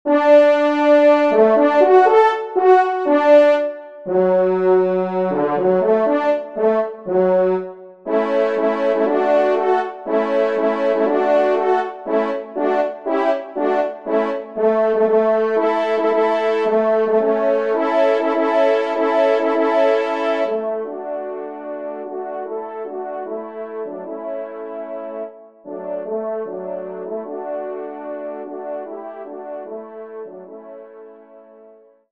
Genre : Divertissement pour Trompes ou Cors
Trompe 2  (en exergue)